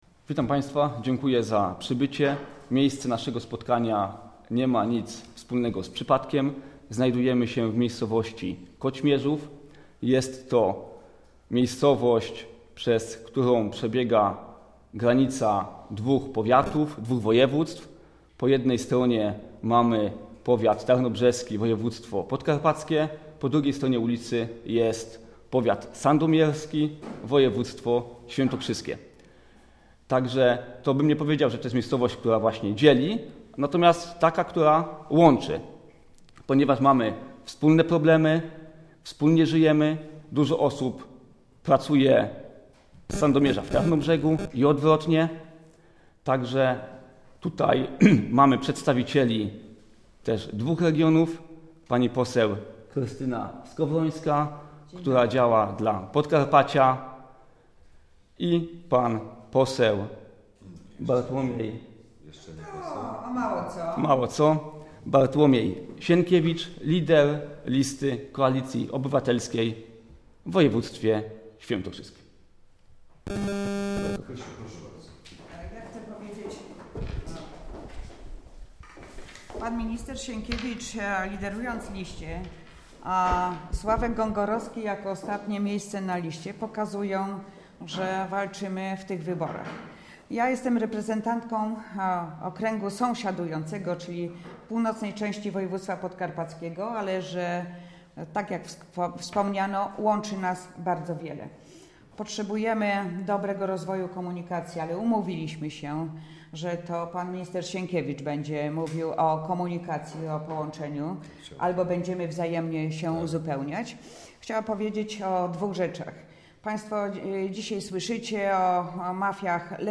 Sandomierz: Konferencja prasowa przedstawicieli Koalicji Obywatelskiej.